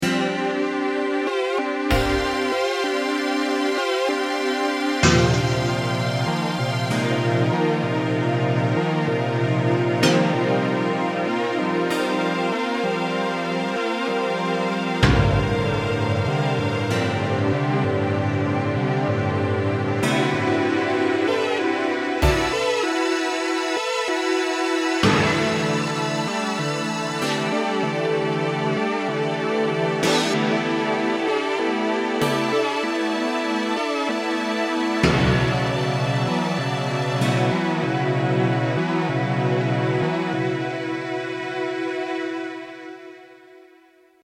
Drum plus Strings
Category: Sound FX   Right: Personal
Tags: Roland U-110 Synth sounds Roland U-110 sample Roland Roland U-110 sounds